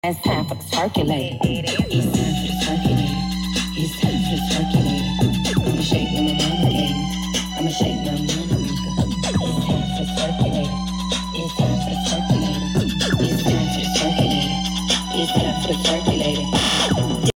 Harbor freight boombox getting down!